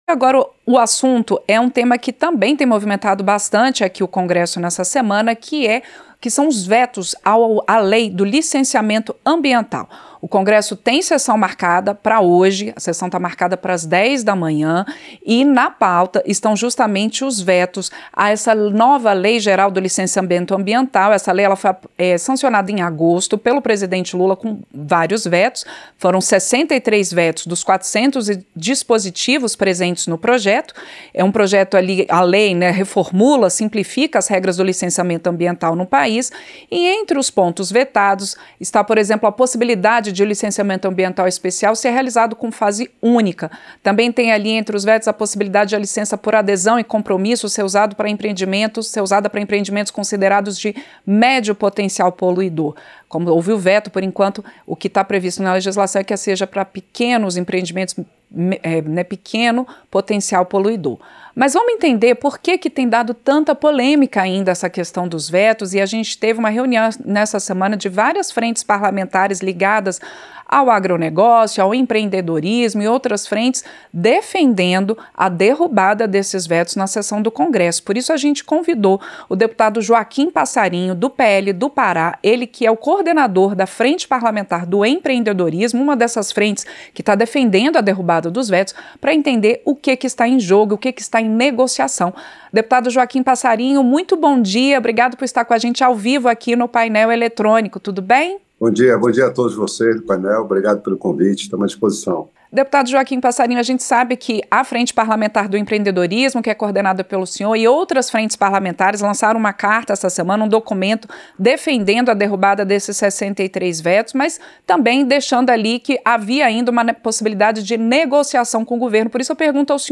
Entrevista - Dep. Joaquim Passarinho (PL-PA)